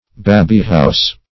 Search Result for " babyhouse" : The Collaborative International Dictionary of English v.0.48: Babyhouse \Ba"by*house`\, n. A place for children's dolls and dolls' furniture.